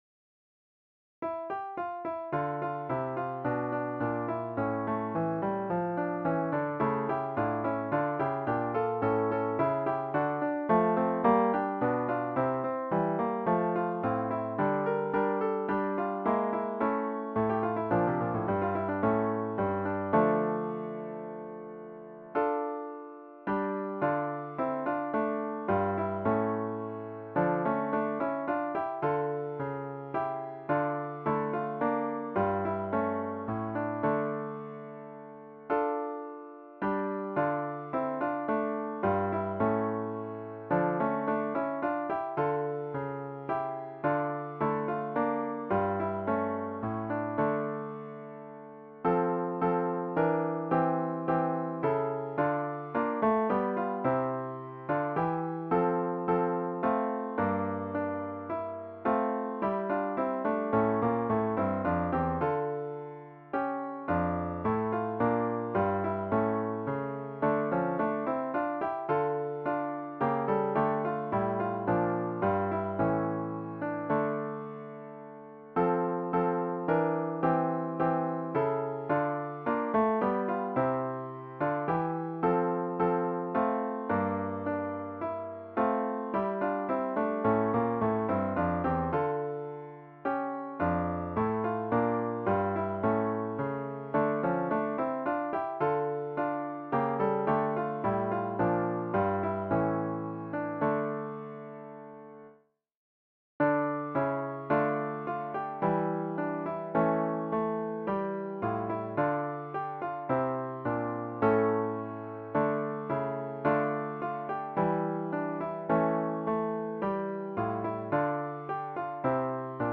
These early Baroque dances
There are a total of six movements.